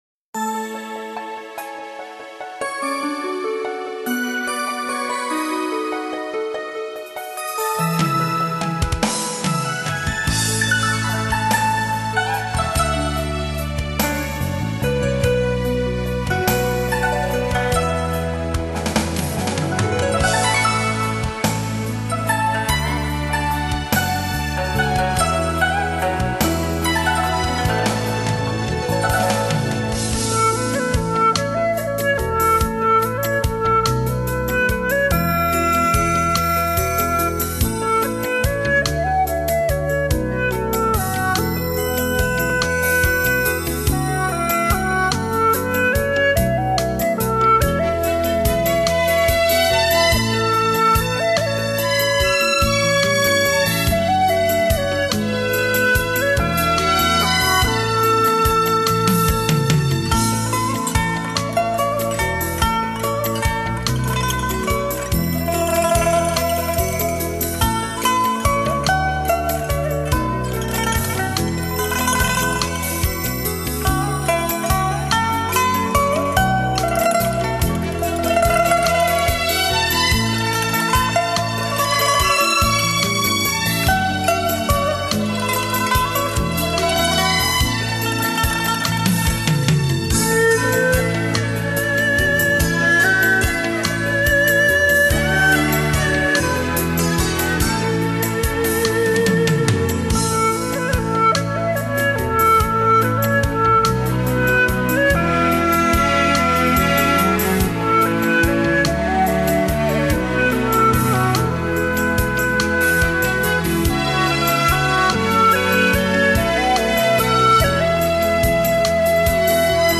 悠扬绵长的音色
轻如丝绸灵性质感无边无际纯天然的聆听感受